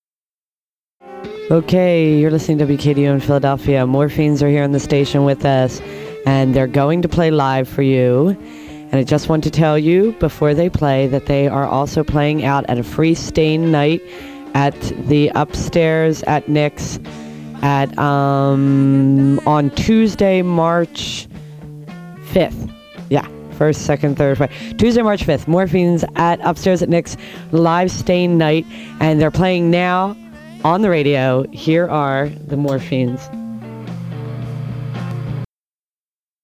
bass
side guitar
drums